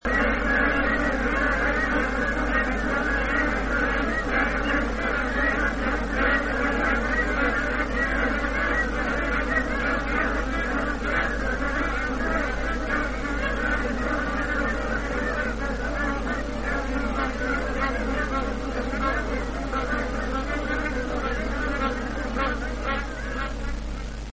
Obie płci wydają głośne okrzyki, przy czym okrzyki samców brzmiązwykle wyżej niż samic. Gdy odezwie się samiec, natychmiast odpowiada mu samica.
głosy